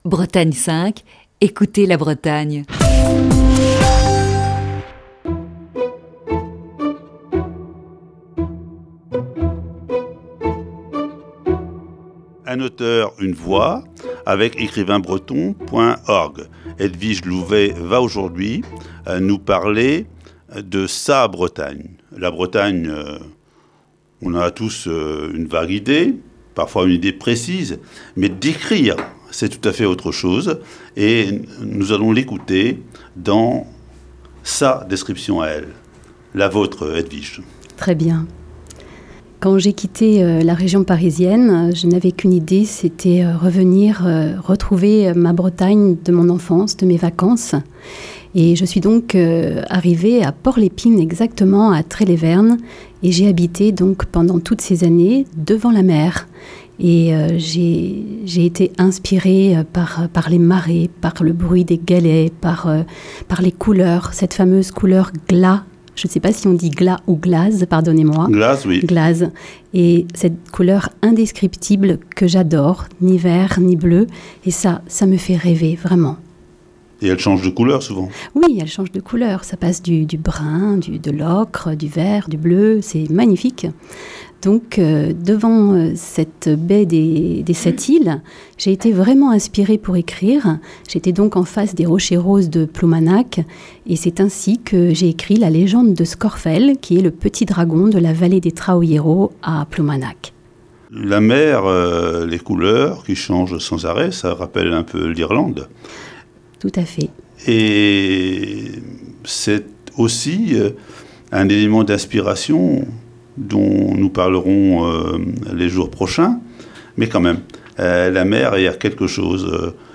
Ce matin, voici la deuxième partie de cet entretien.